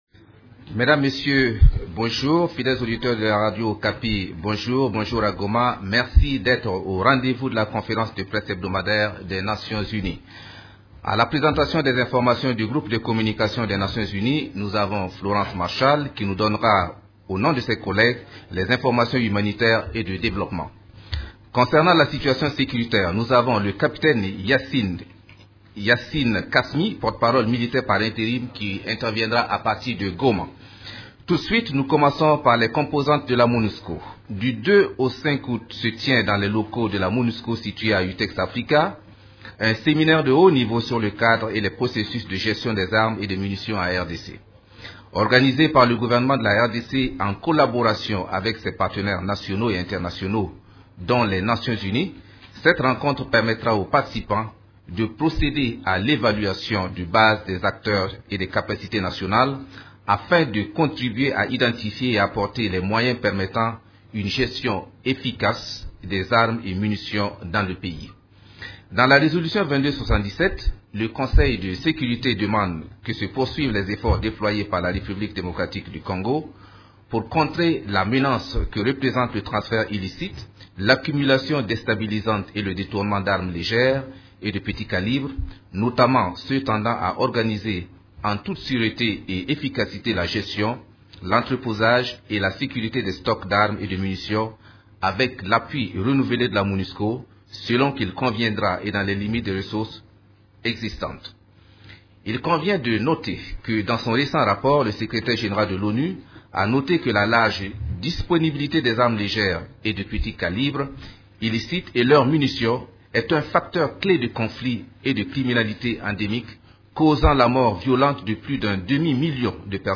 Conférence de presse du 3 août 2016
La conférence de presse hebdomadaire des Nations unies du mercredi 3 août à Kinshasa a porté sur les activités des composantes de la MONUSCO, les activités de l’équipe-pays et la situation militaire.